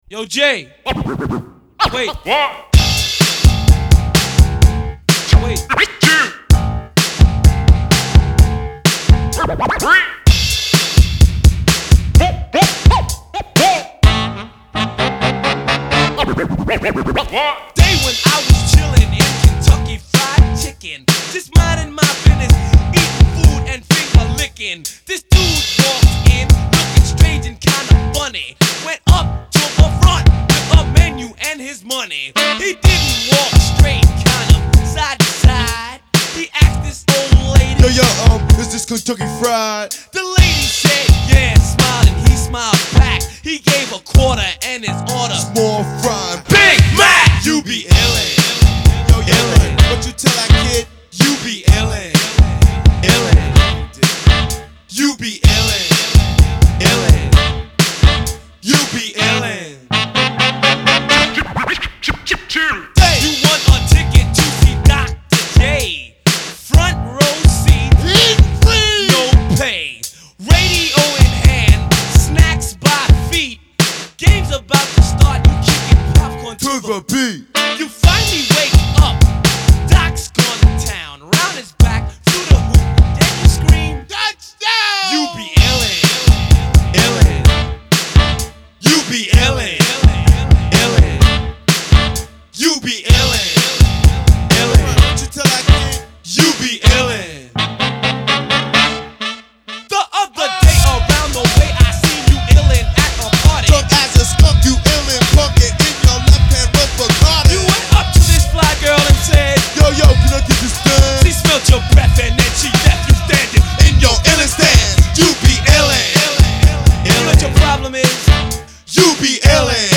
Жанр: Hip hop, rap, Rock